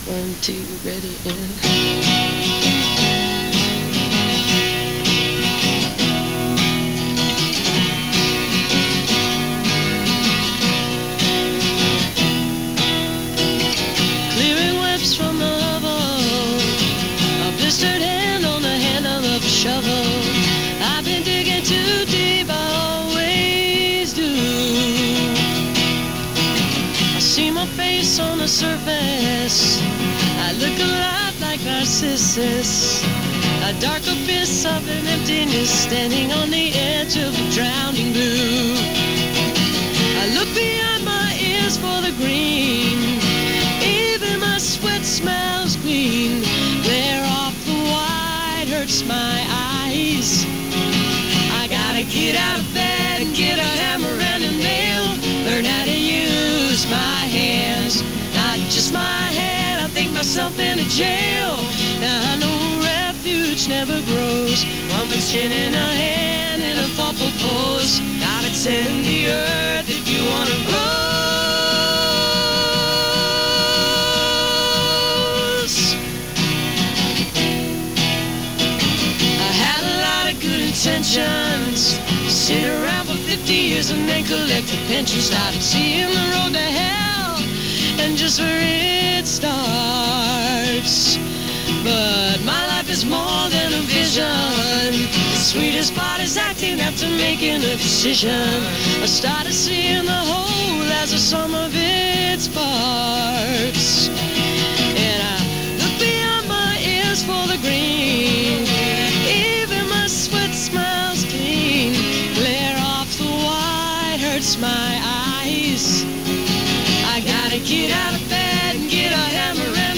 acoustic duo show